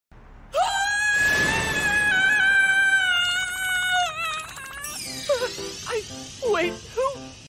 Midoriya Scream